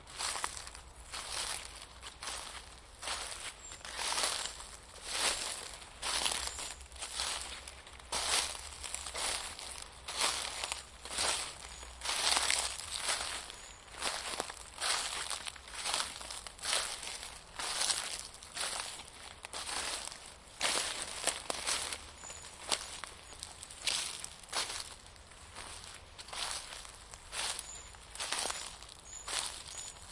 在森林的干叶上行走脚步声
描述：走在干树叶在森林里。叶子在鞋子的压力下沙沙作响。这是冬天和早春的转折点。雪已融化到处。使用ZOOM H2n进行了修改。